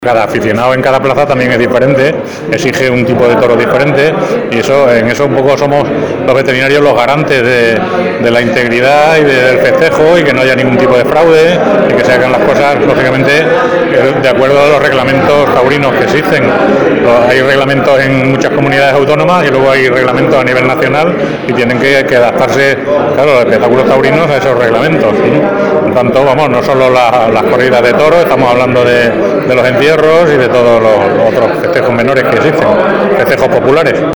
La segunda de las jornadas de la XXVI Semana Cultural del Club Taurino ‘Almodóvar’